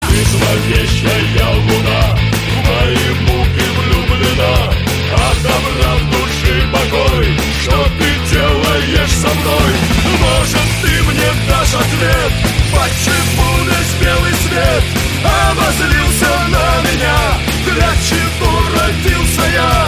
• Качество: 104, Stereo
громкие
русский рок
панк-рок